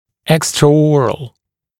[ˌekstrə’ɔːrəl] [ˌэкстрэ’о:рэл] внеротовой